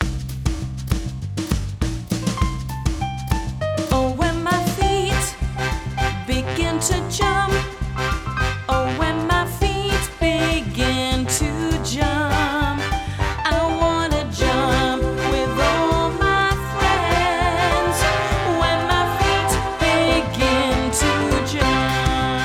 (High energy, Gross motor)